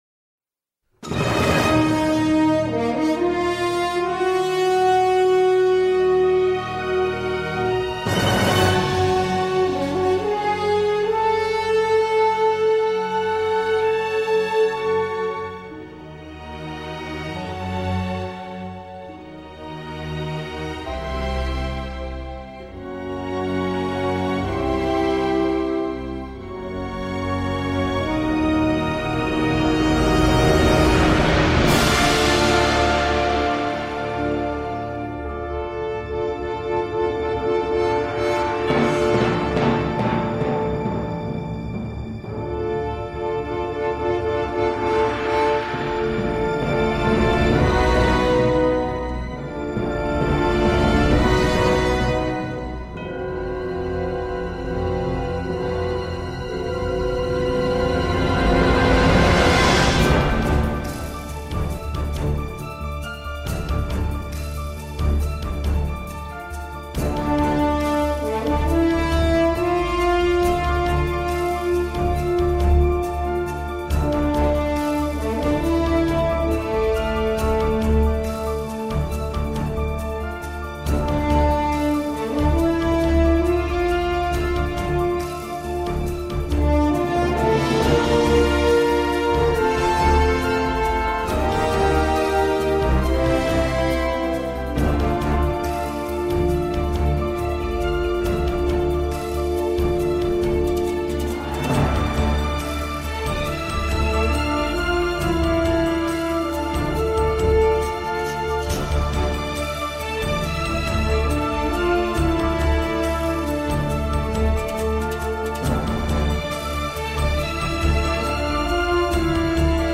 Un petit harmonica ironique